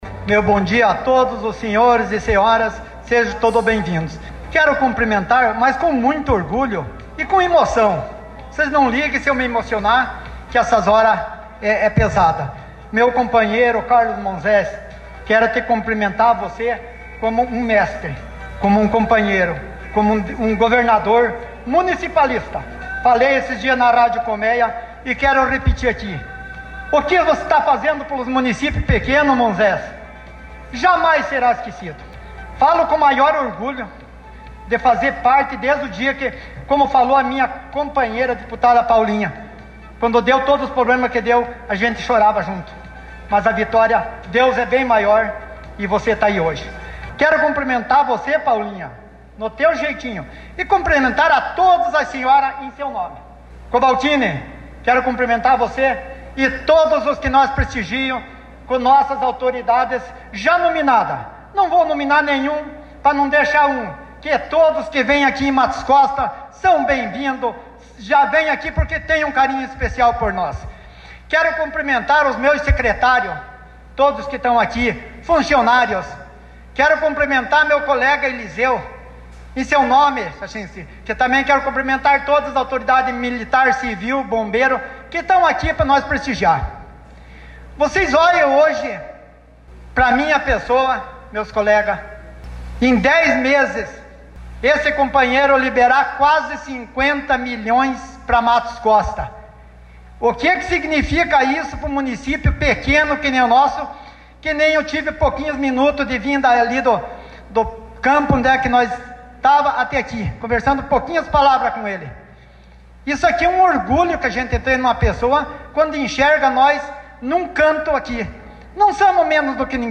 PREFEITO-DE-MATOS-COSTA-PAULO-CAMARGO.mp3